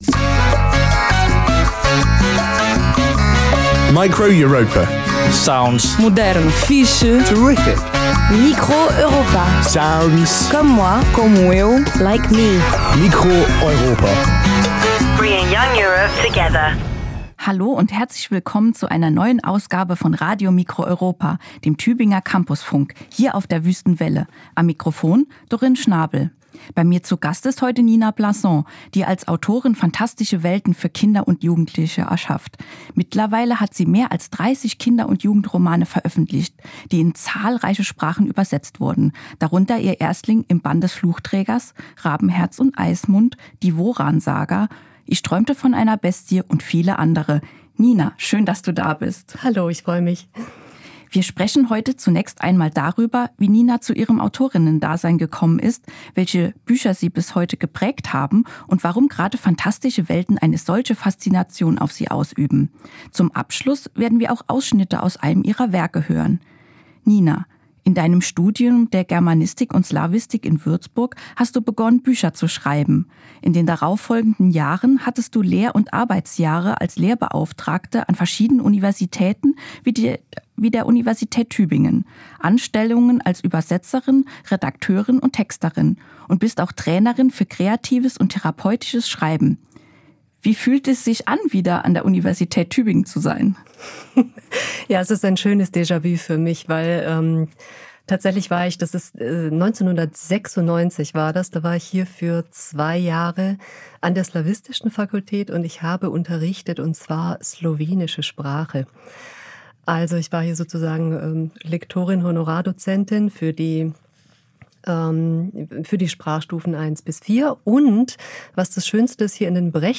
Zum Abschluss hören wir von ihr selbst gelesene Ausschnitte aus einem ihrer Werke.
Form: Live-Aufzeichnung, geschnitten